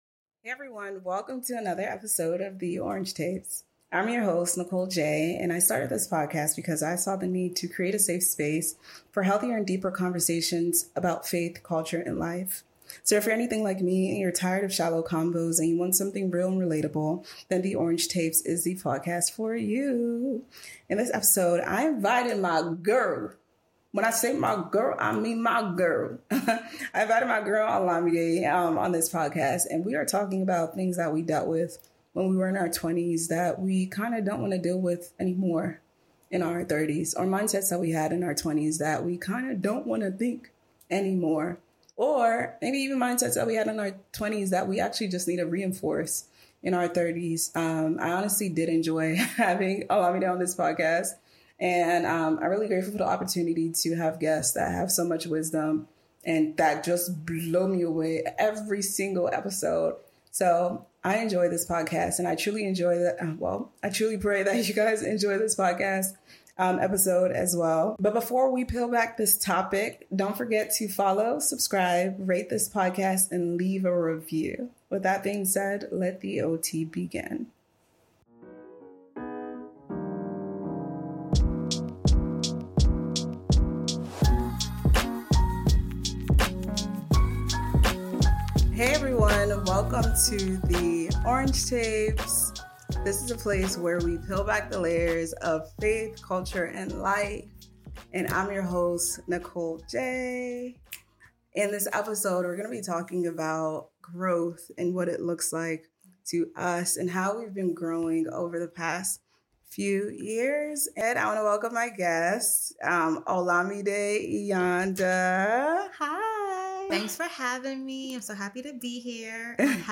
A Conversation on Growth